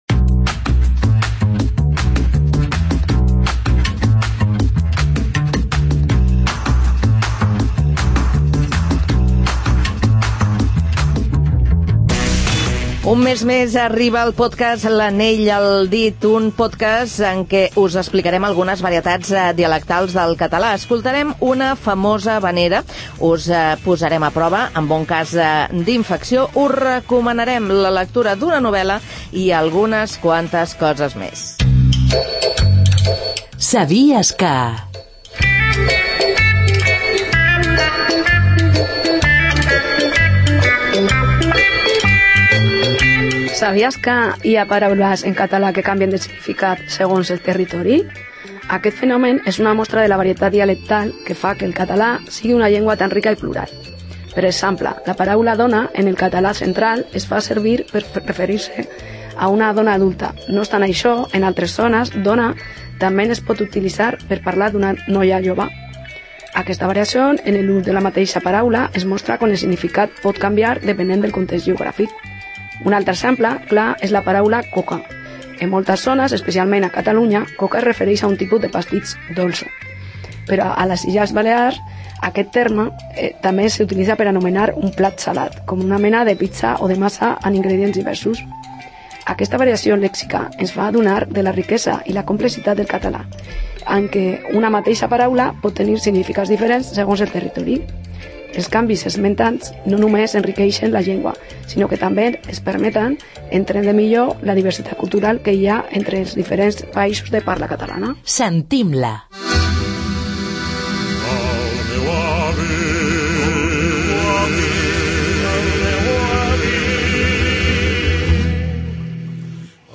El magazín 'Faves comptades' ha acollit una nova edició de la secció l''Anell al Dit', dedicada a la llengua i cultura catalanes. Tres alumnes del nivell Intermedi 2 del Servei Local de Català de Sant Cugat ens porten un recorregut pel lèxic català.